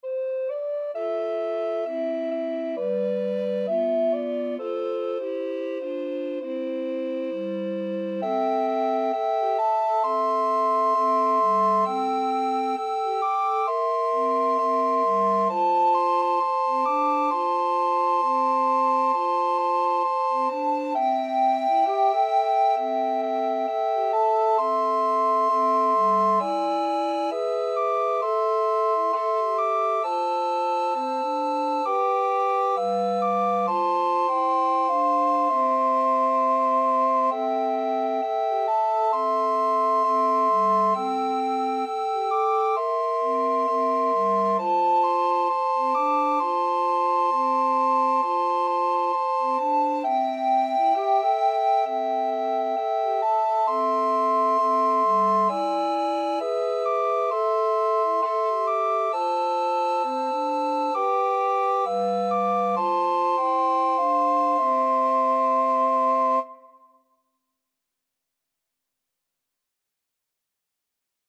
Soprano RecorderAlto RecorderTenor RecorderBass Recorder
4/4 (View more 4/4 Music)
Traditional (View more Traditional Recorder Quartet Music)